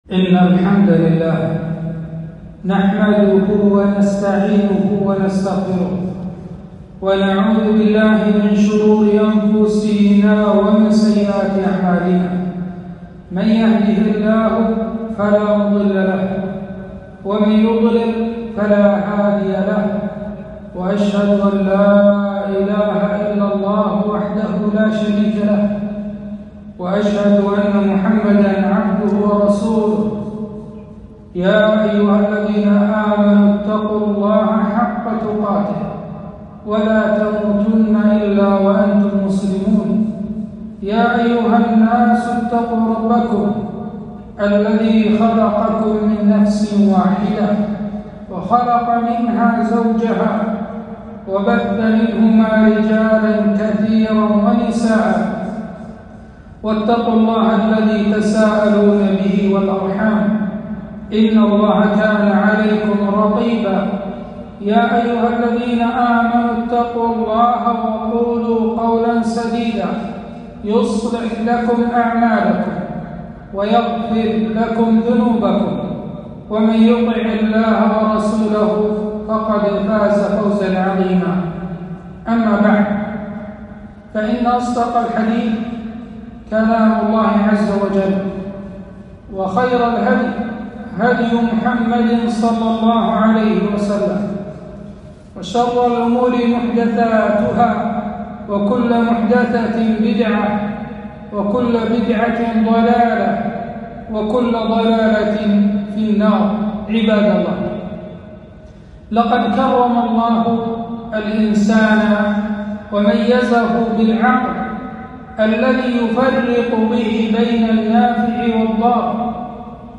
خطبة - آفة الآفات الخمور والمخدرات